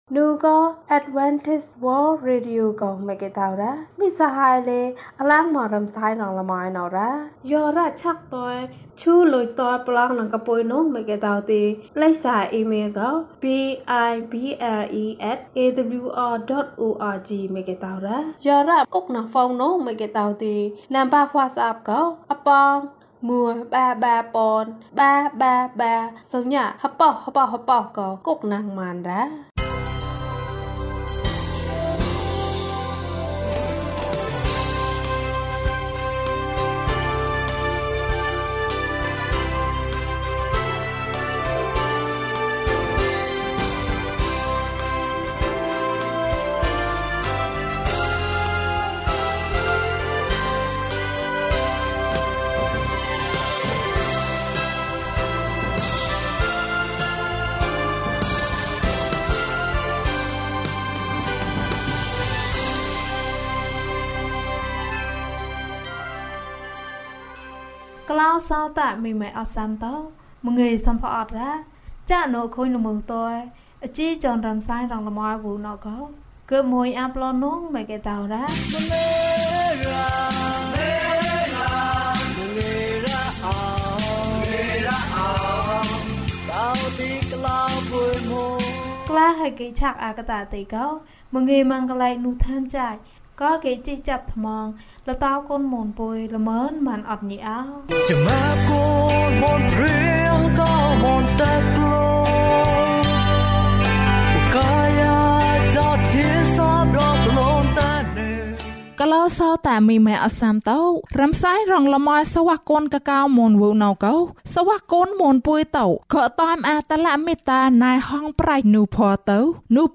ဘုရားသခင်က ကောင်းတယ်။၀၁ ကျန်းမာခြင်းအကြောင်းအရာ။ ဓမ္မသီချင်း။ တရားဒေသနာ။